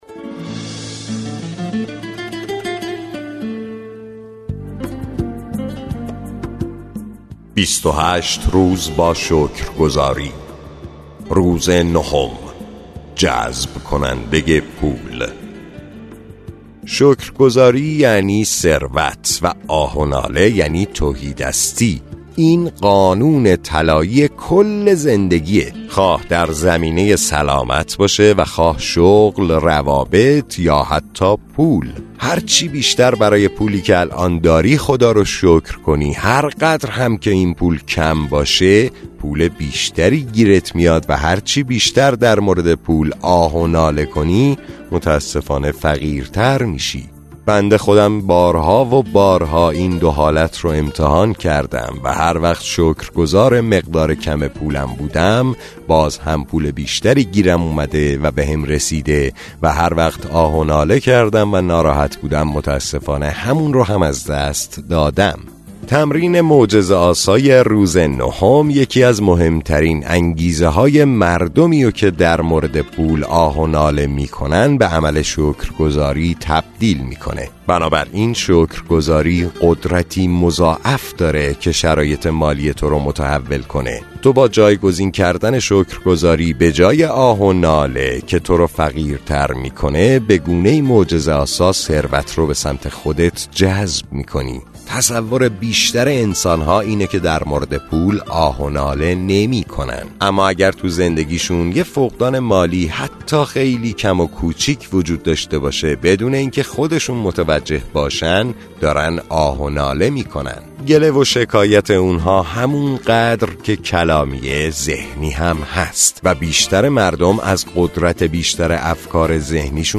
کتاب صوتی معجزه شکرگزاری – روز نهم